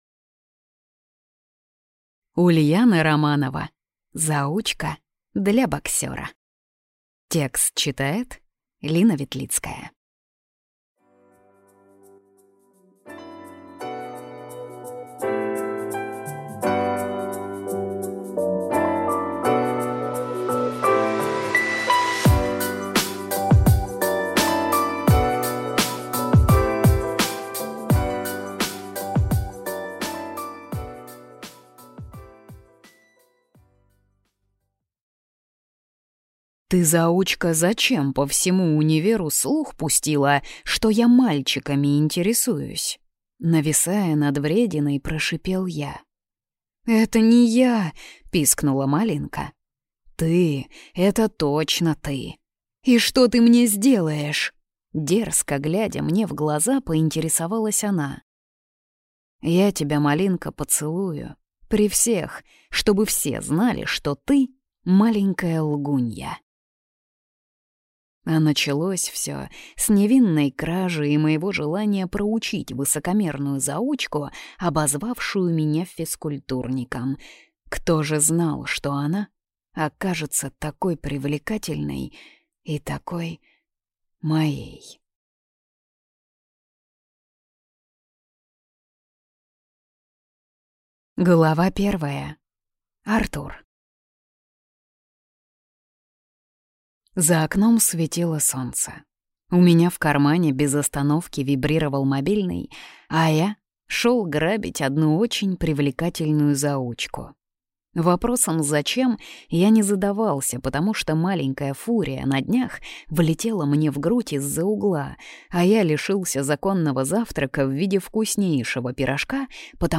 Аудиокнига Заучка для боксера | Библиотека аудиокниг